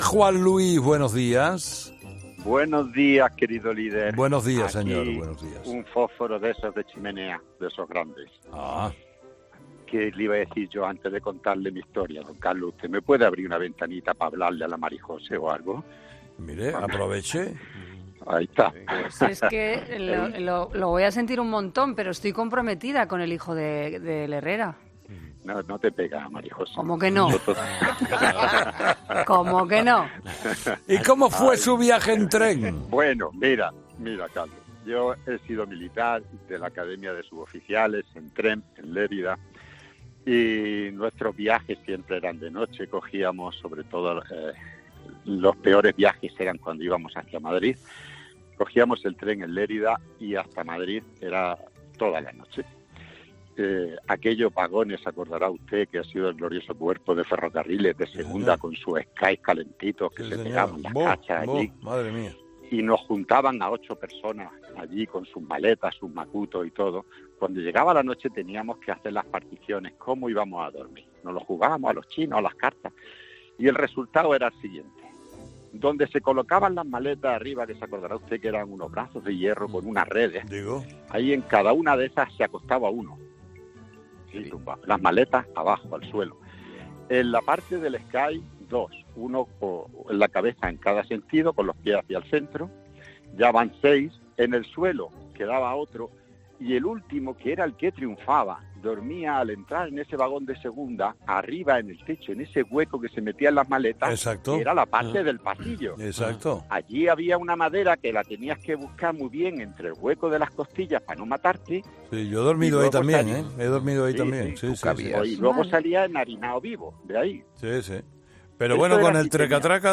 Este martes los oyentes de Herrera han relatado sus anécdoras más locas a bordo de este medio de transporte.